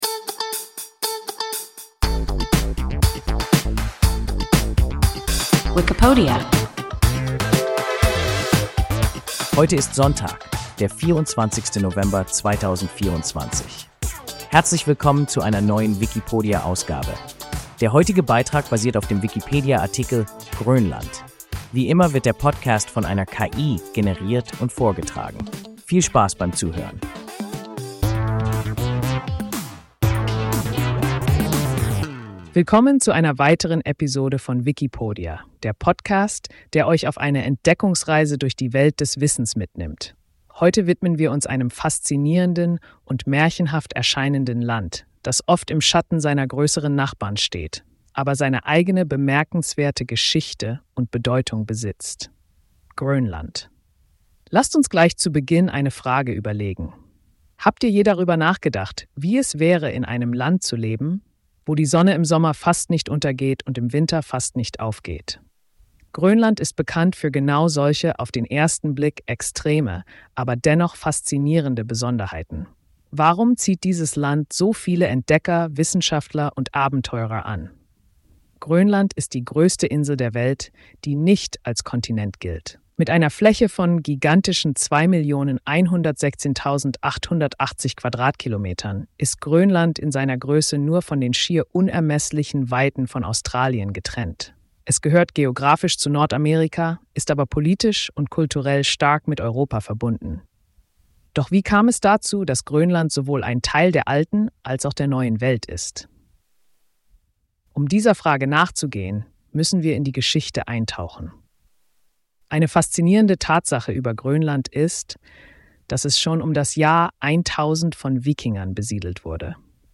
Grönland – WIKIPODIA – ein KI Podcast